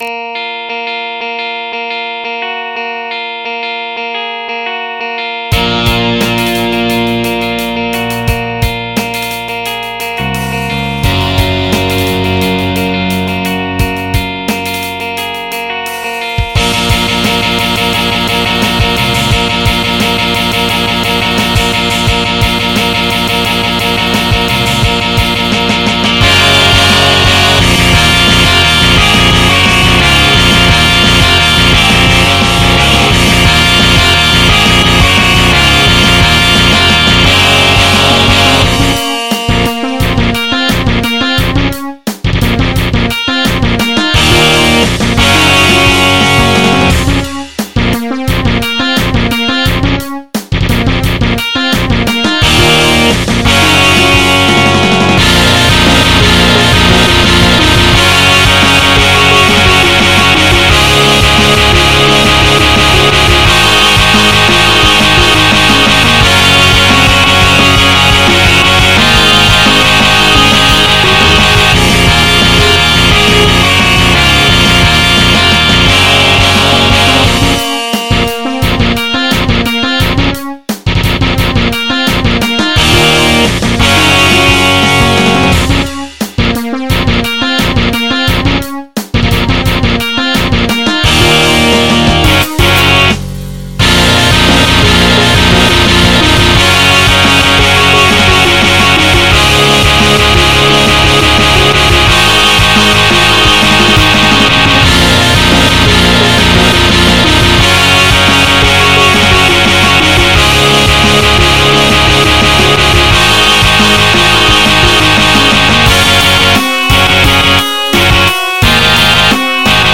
MIDI 45.33 KB MP3 (Converted) 2.9 MB MIDI-XML Sheet Music